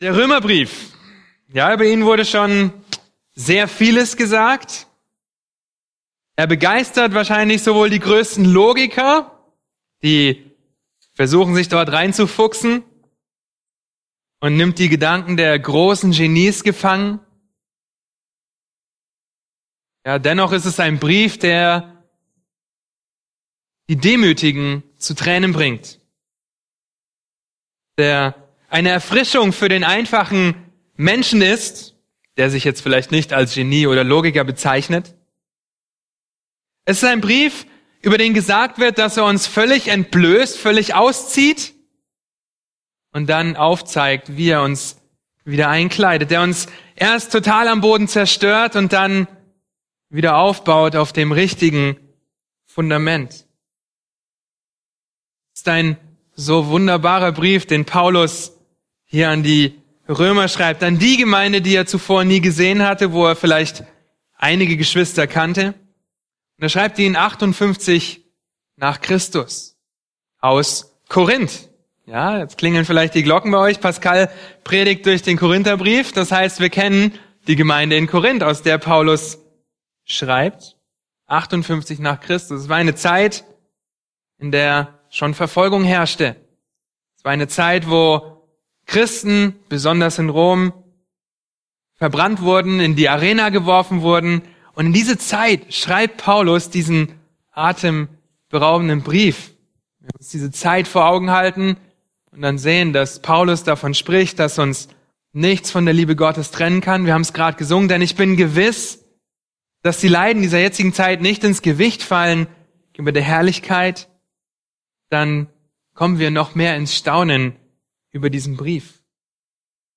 Eine predigt aus der serie "Markus."